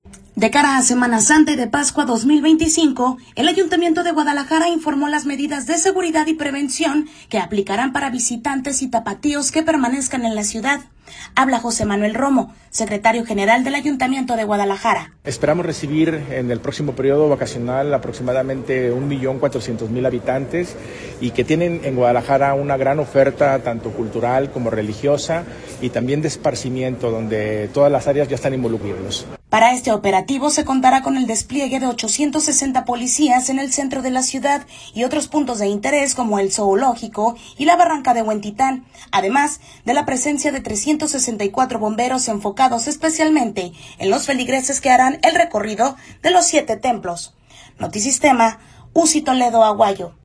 De cara a Semana Santa y de Pascua 2025, el ayuntamiento de Guadalajara informó las medidas de seguridad y prevención que aplicarán para visitantes y tapatíos que permanezcan en la ciudad. Habla José Manuel Romo, Secretario General del ayuntamiento de Guadalajara. esperamos […]